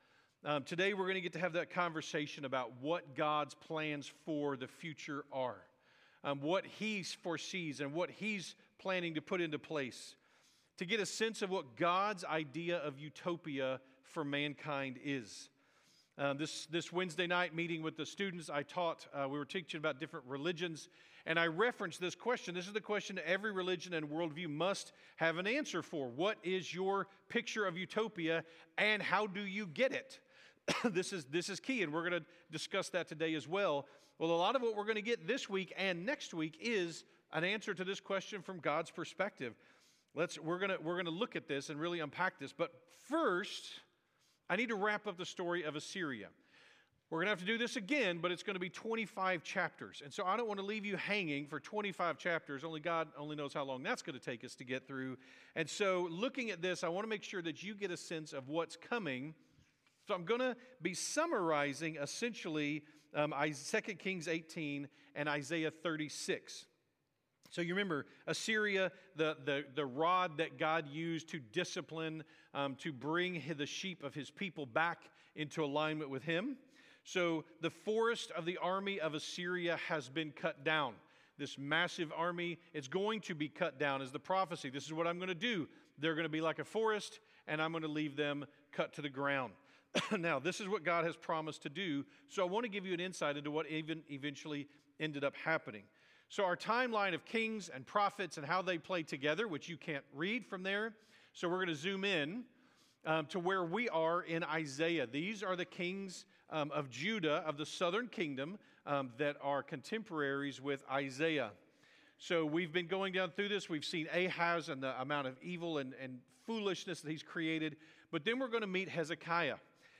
by South Spring Media | Oct 26, 2025 | 2025 Sermons, Isaiah, Isaiah Series | 0 comments